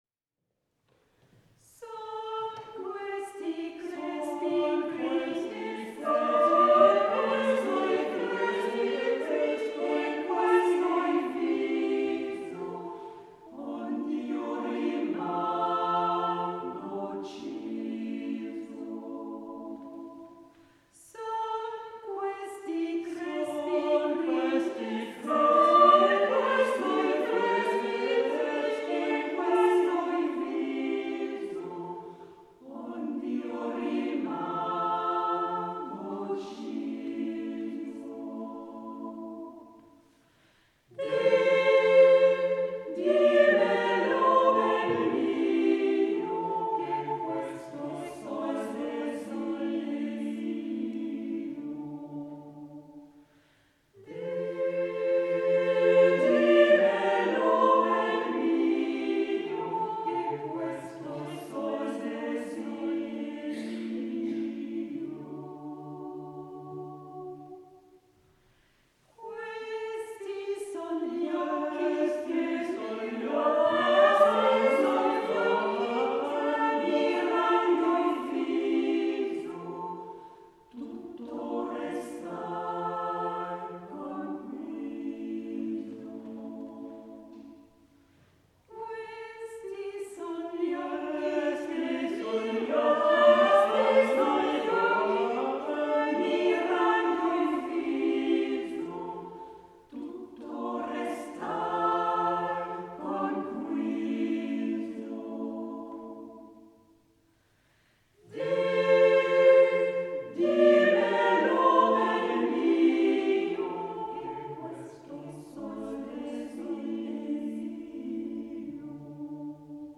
Quelques extraits de Nyon: